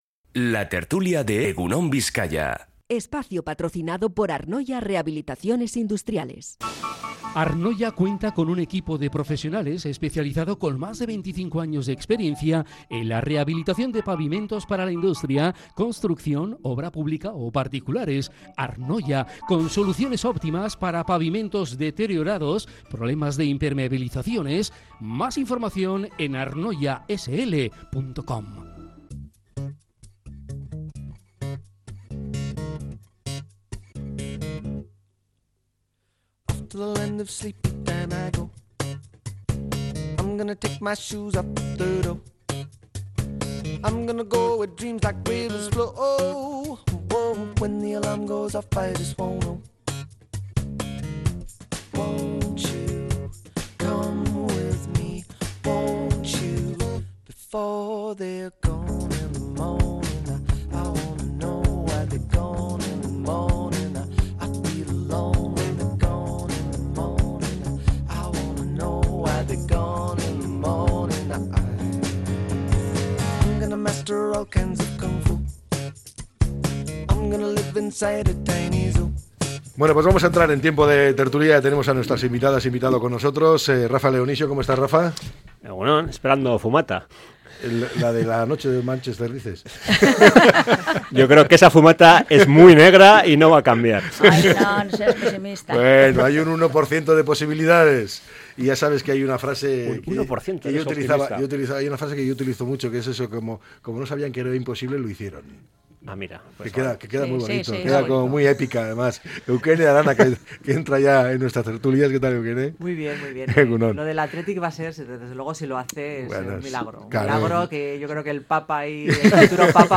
La tertulia 08-05-25.